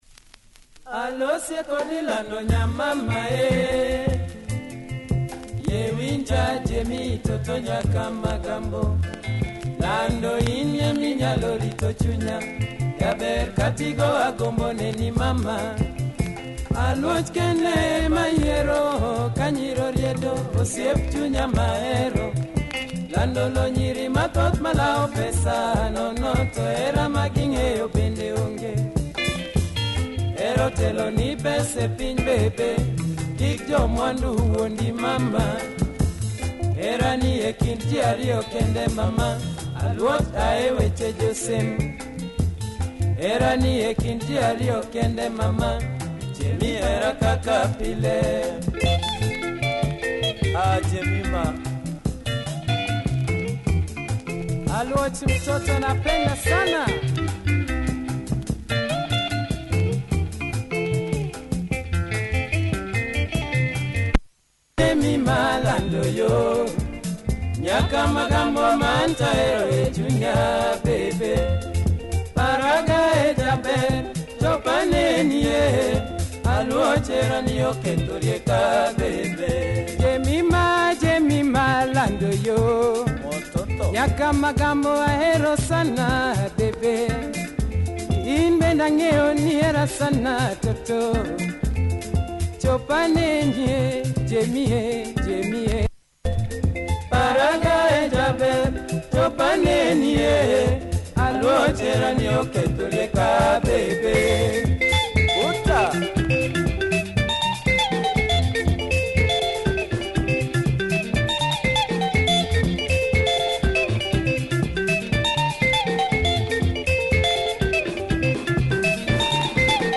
Luo band on this polygram subsidiary label